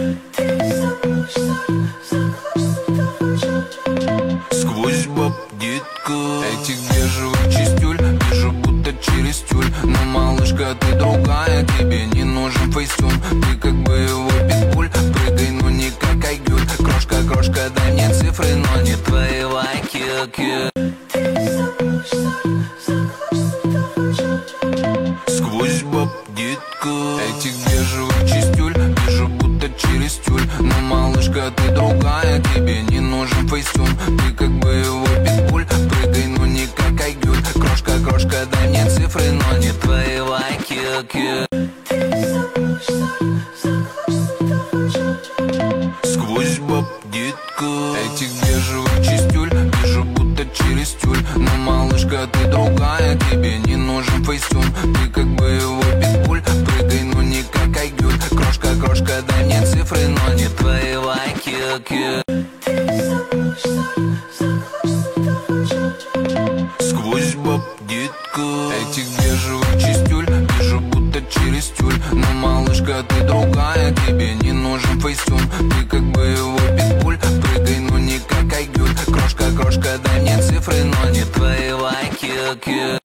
Рэп, Новинки